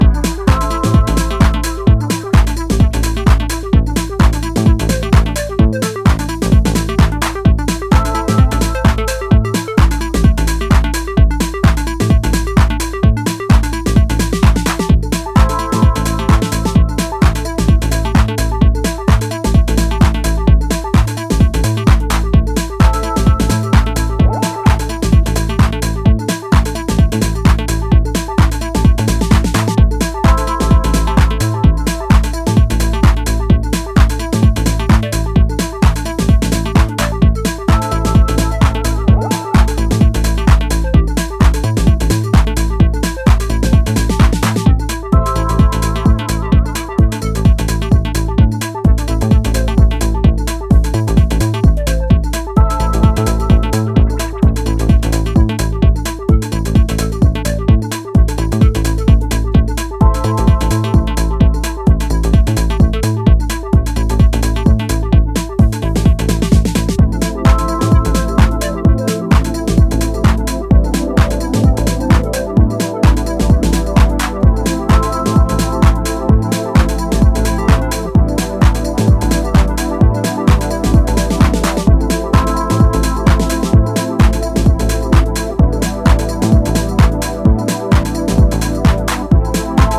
初期デトロイト的ムードも醸すオプティミスティックかつメランコリックなメロディーが心地良い